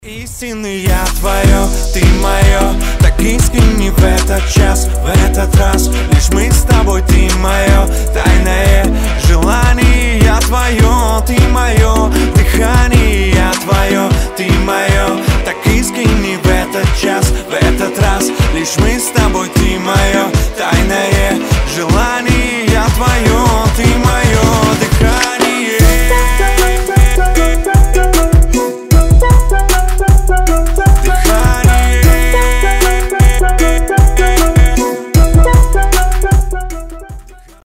• Качество: 320, Stereo
поп
Хип-хоп